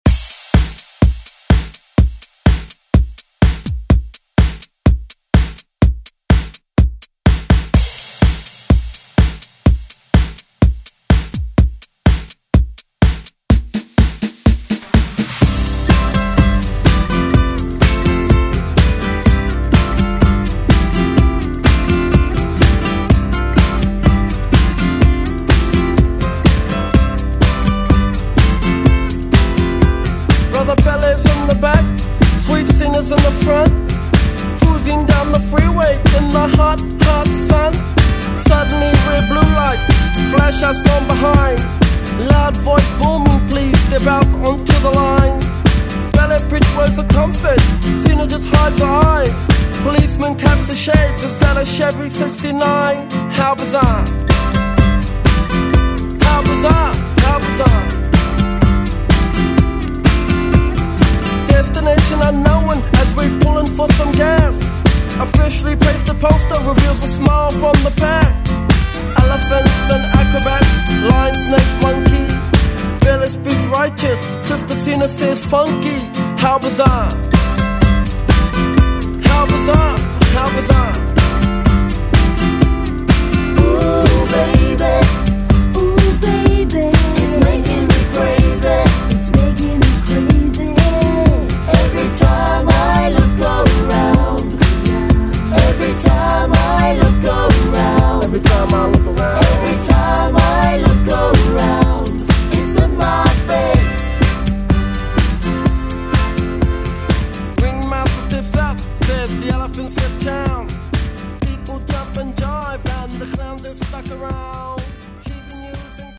Electronic Pop Dance House Music
132 bpm